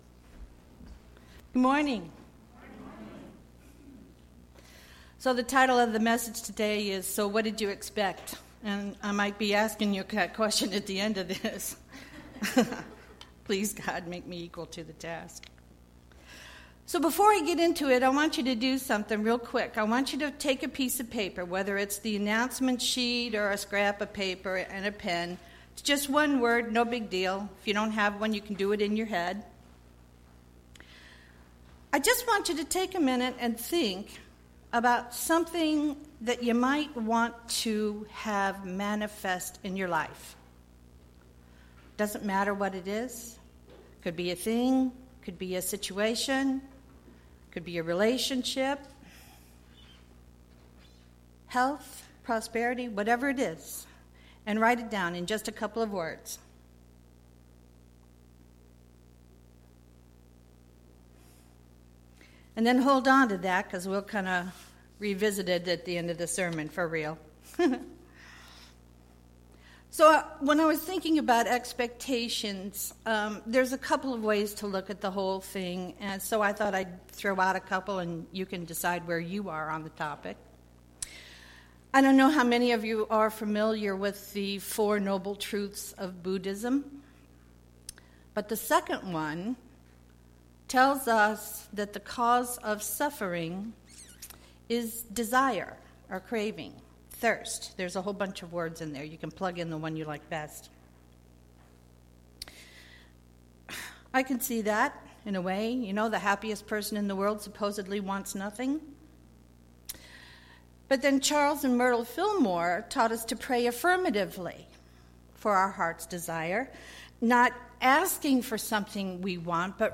Sermons 2016